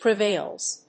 発音記号
• / prɪˈvelz(米国英語)
• / prɪˈveɪlz(英国英語)